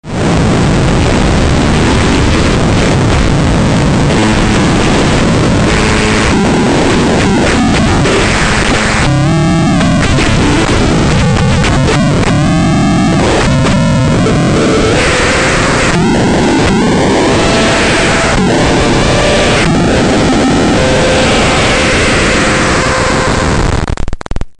这个绿不拉几的玩音是个八度类的单块，用ZAKK的话说，它拥有放屁一样的声音！在4MS里它们叫噪音类效果器（也确实很噪音）